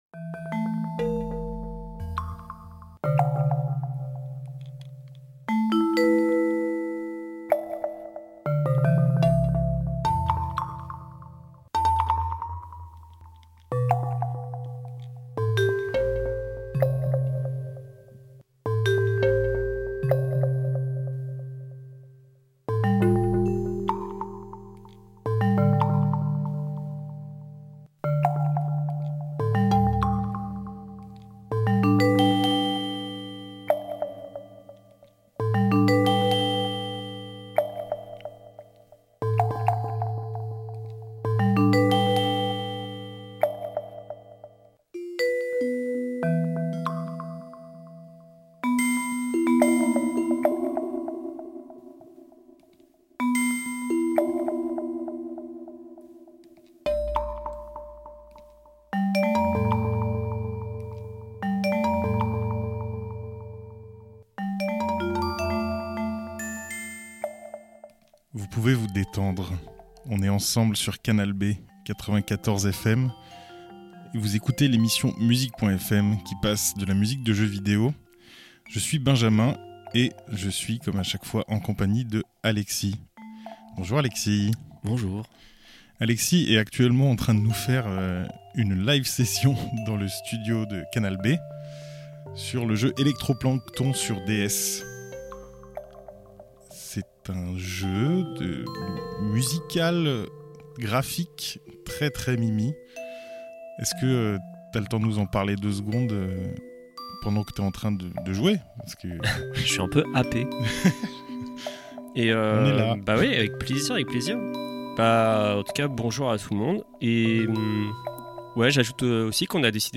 Live session